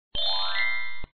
1 channel
3_soundDestroy.mp3